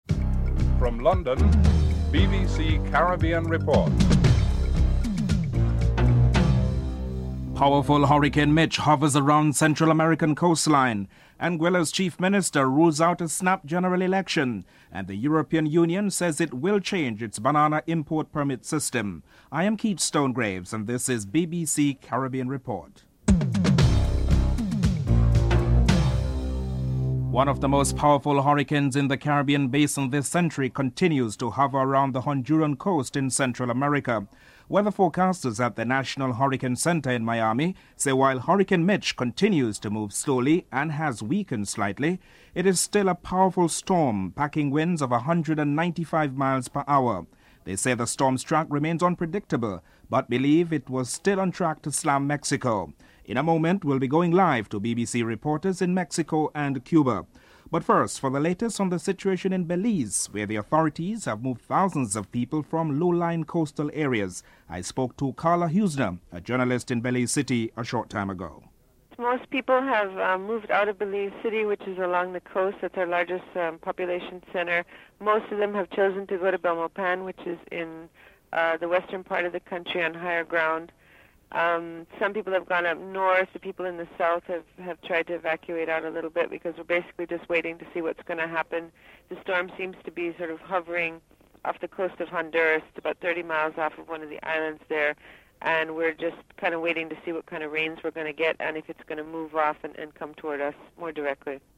1. Headlines (00:00-00:25)
Chief Minister Hubert Hughes and Leader of the Opposition in the House of Assembly Osbourne Fleming are interviewed (06:08-09:33)